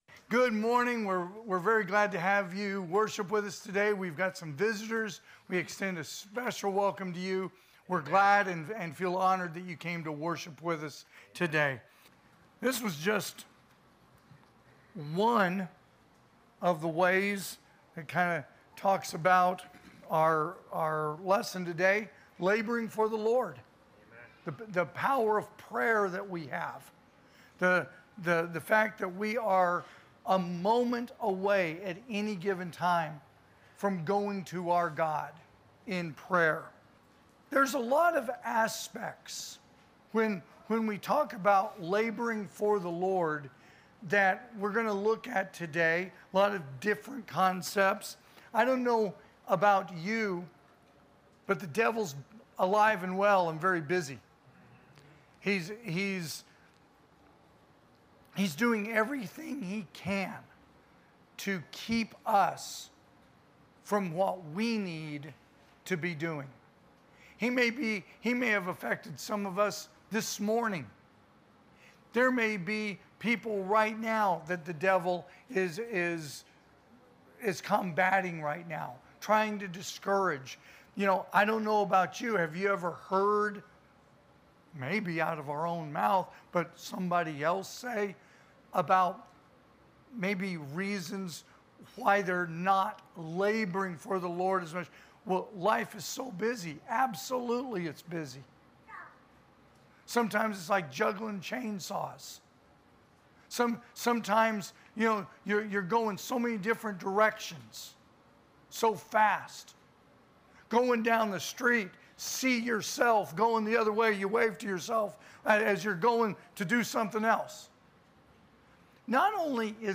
2025 (AM Worship) "Laboring For The Lord"